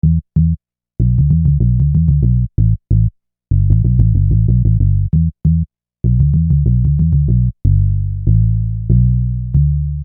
Bass 24.wav